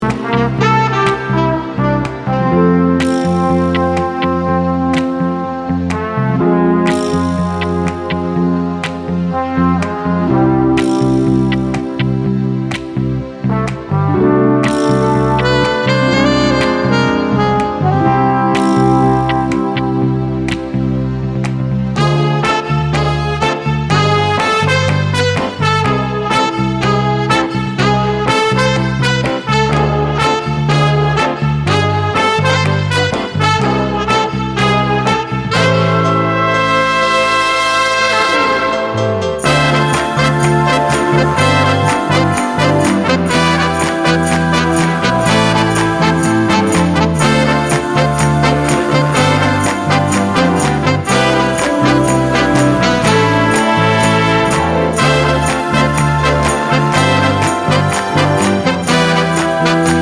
Minus Drums Crooners 3:28 Buy £1.50